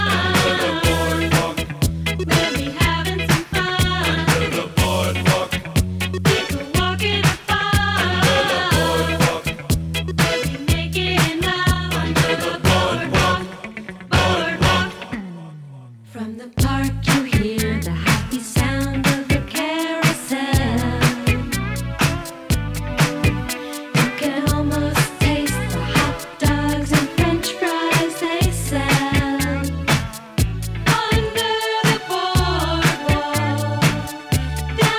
Жанр: Поп музыка / Рок / R&B / Альтернатива / Соул